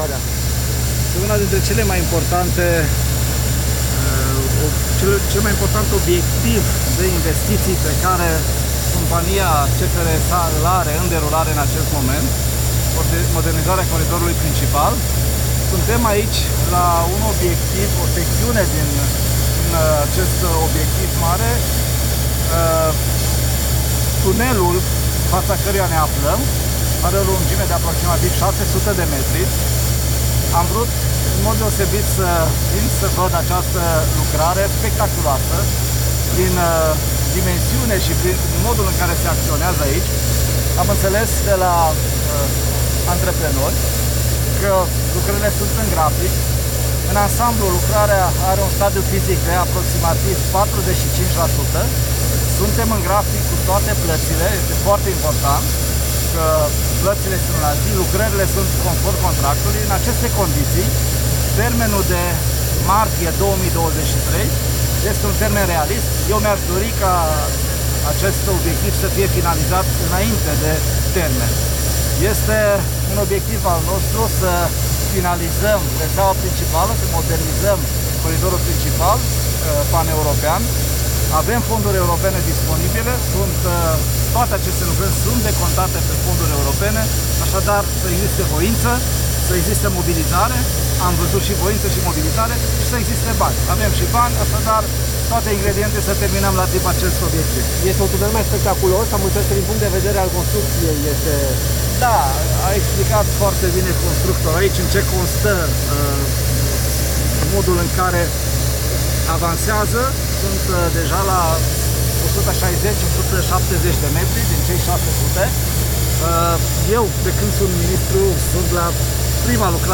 Tunelul, care are o lungime de 603 metri, este în curbă, iar lucrările sunt în grafic, spune ministrul Transporturilor, Lucian Bode, care a vizitat șantierul.